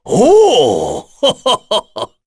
Dakaris-Vox_Happy6_kr.wav